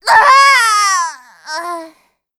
assassin_w_voc_die02_a.ogg